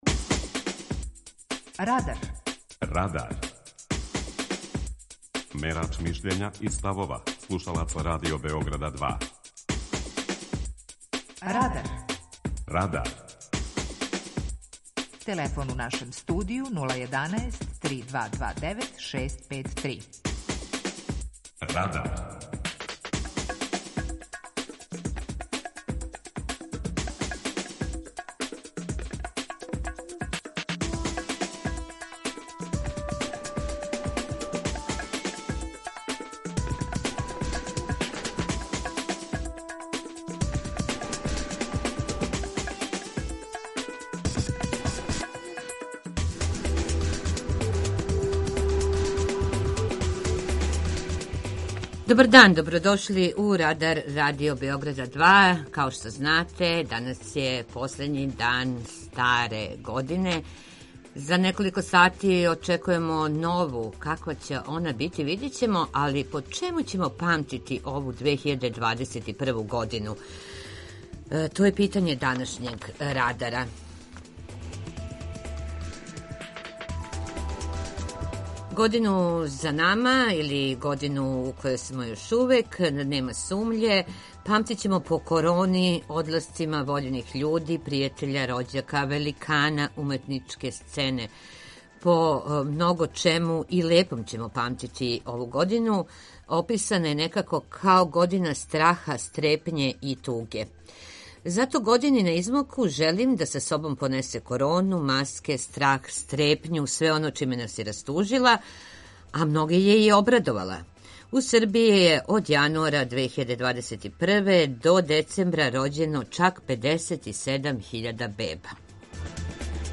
Питање Радара: Шта треба заборавити, а шта памтити из 2021. године? преузми : 19.10 MB Радар Autor: Група аутора У емисији „Радар", гости и слушаоци разговарају о актуелним темама из друштвеног и културног живота.